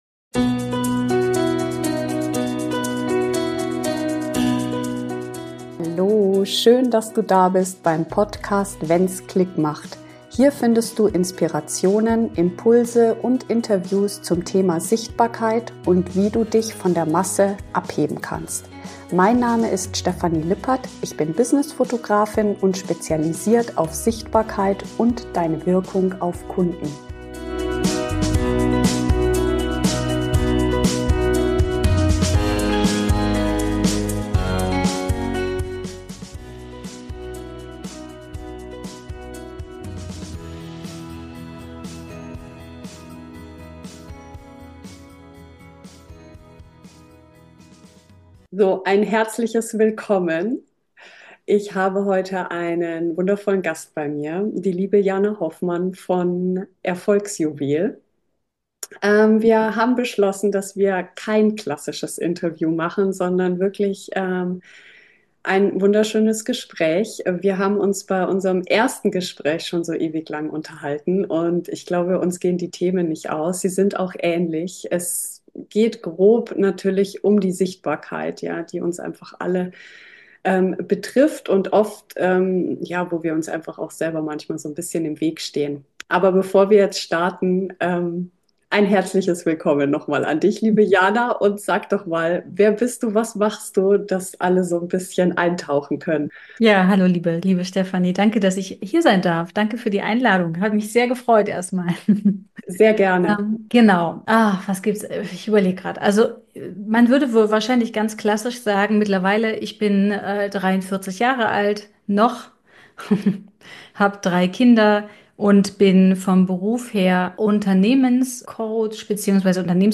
Zwei Unternehmerinnen, zwei Geschichten – und viele echte Einblicke in Höhen, Tiefen und das große „Warum“ hinter dem, was wir tun. Ein Gespräch für alle, die sichtbar(er) werden wollen, mit dem Gedanken an Selbstständigkeit spielen oder einfach Inspiration für ihren eigenen Weg suchen.